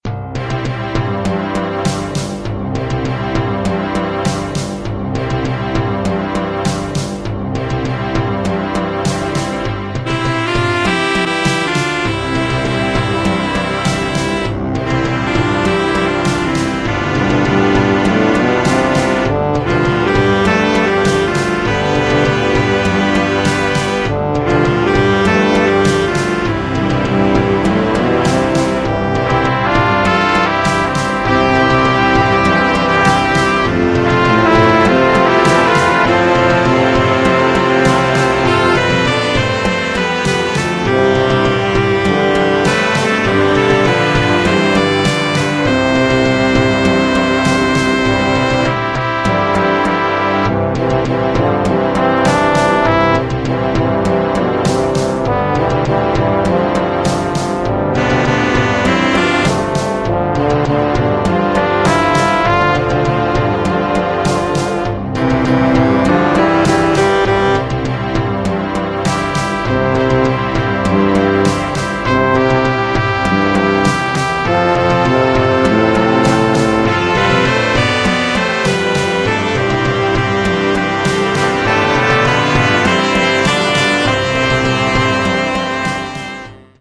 Composition and arrangement for Concert Band